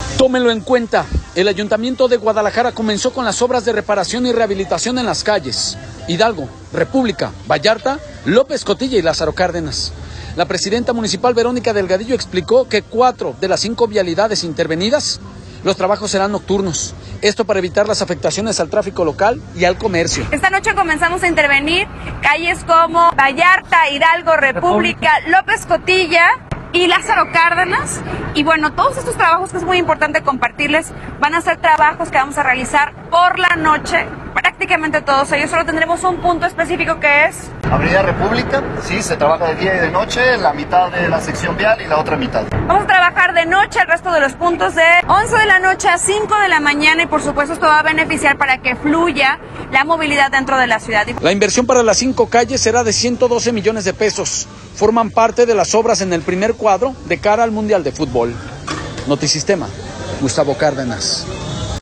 La presidenta municipal, Verónica Delgadillo, explicó que en cuatro de las cinco vialidades los trabajos serán nocturnos, esto para evitar las afectaciones al tráfico local y el comercio.